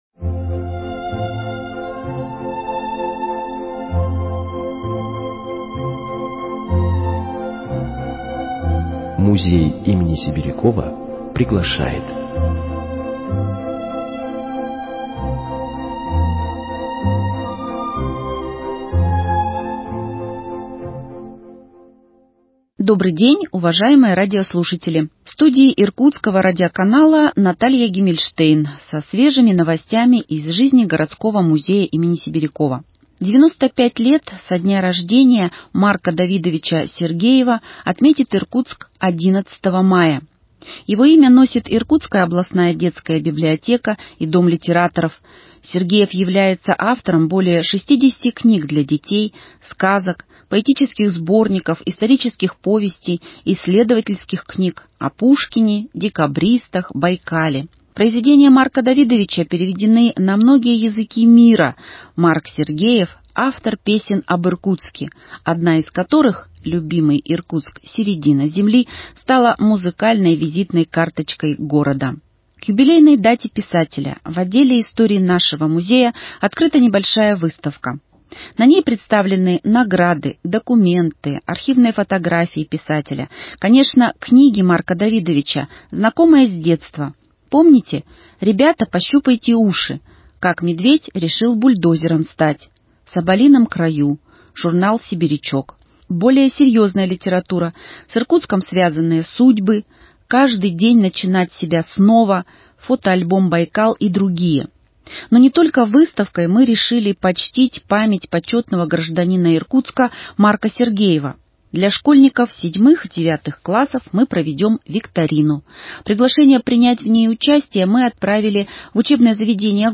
Еженедельная передача, выходящая по пятницам.